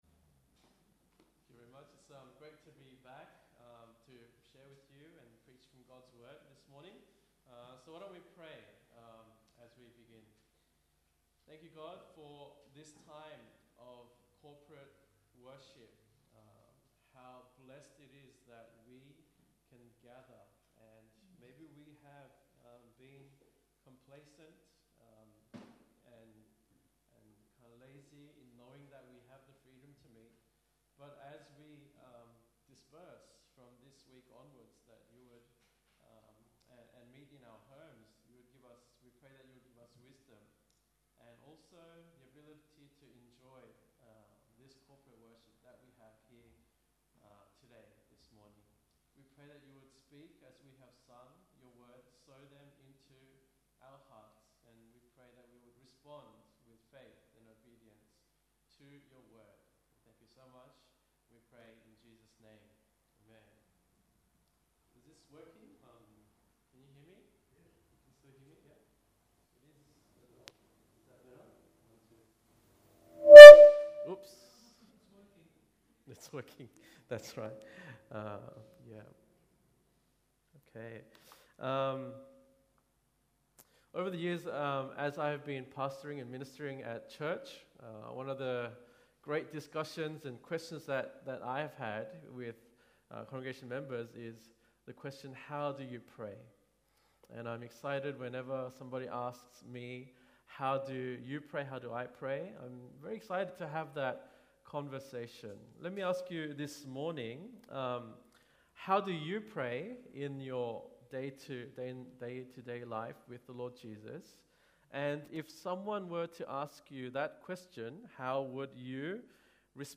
Hosea 6:1-3 Service Type: Sunday Morning « Proverbial Wisdom